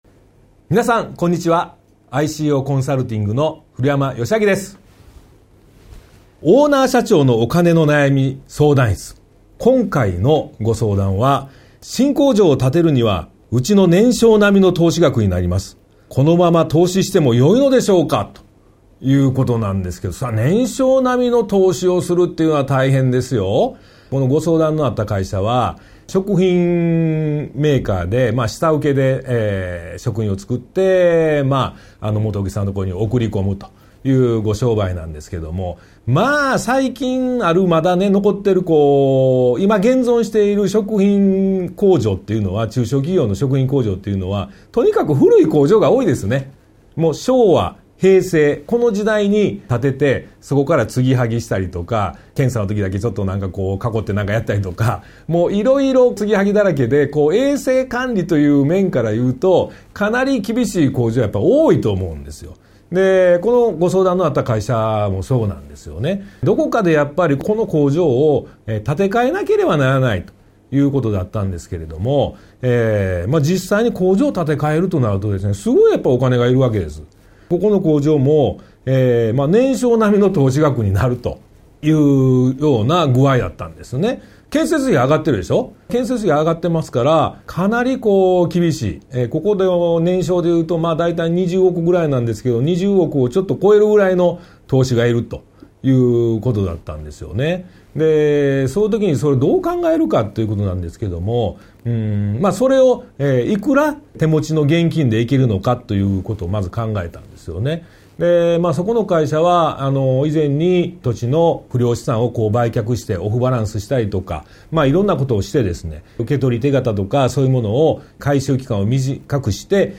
ワンポイント音声講座 相談5：新工場を建てるには、うちの年商並みの投資額になります。